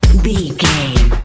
Ionian/Major
drum machine
synthesiser
hip hop
Funk
neo soul
acid jazz
energetic
bouncy
Triumphant
funky